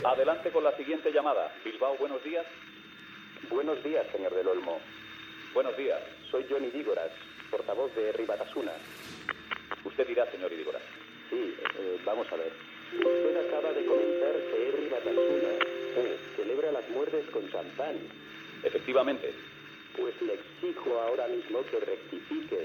Enfrontament dialèctic entre Luis del Olmo i el portaveu d'Herri Batasuna Jon Idígoras, després de l'assassinat del capità de Farmàcia Alberto Martín Barrios, el dia anterior (Havia estat segrestat per ETA el 5 d'octubre)
Info-entreteniment